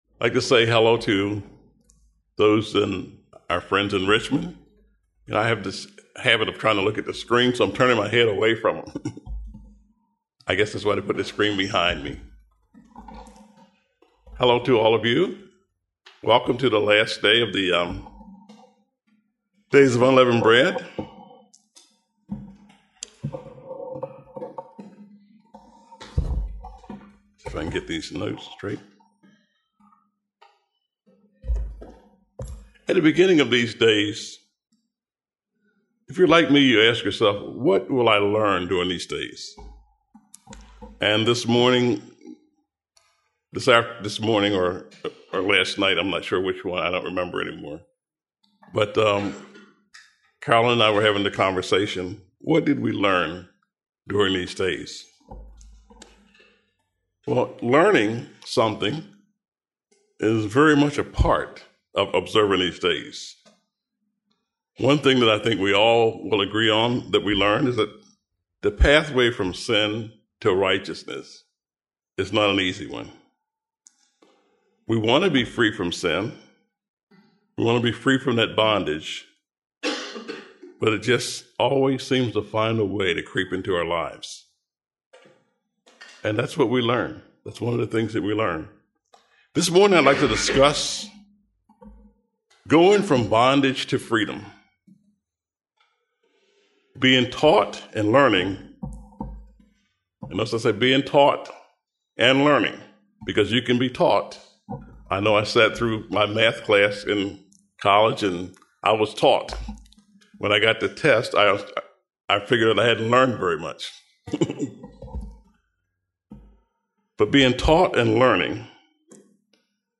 Sermons
Given in Columbia, MD